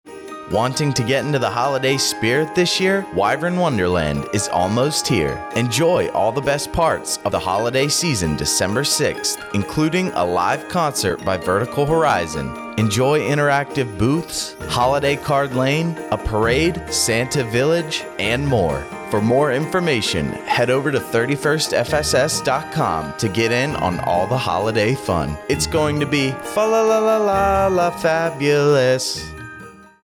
An American Forces Network (AFN) Aviano radio spot highlighting the upcoming Wyvern Wonderland event at Aviano Air Base Italy. Wyvern Wonderland is an annual celebration the provides the Aviano Community as touch of home and a taste of the holiday season.